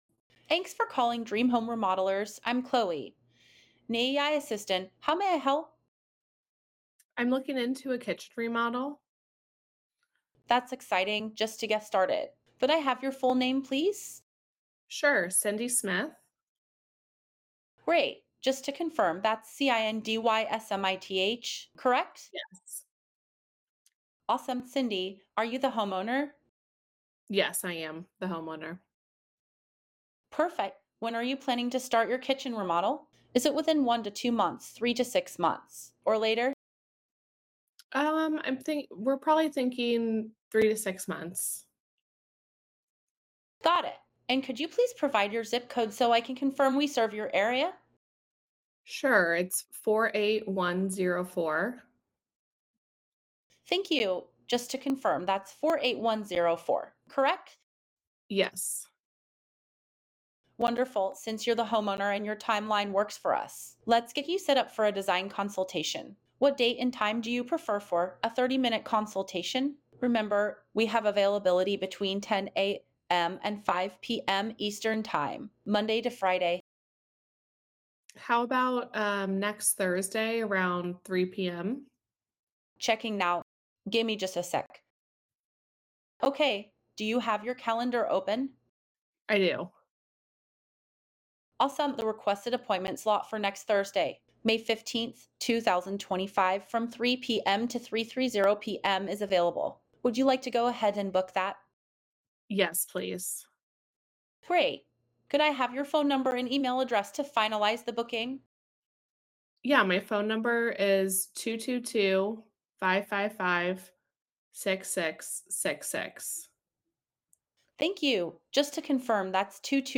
Listen to a real-world example of how our AI Voice Agent handles after-hours calls for a home remodeling business. In this demo, the AI seamlessly answers the call, captures essential project details from the homeowner, and successfully schedules a consultation-all without any human intervention.
AI Sales Agent Demo
• Natural, conversational dialogue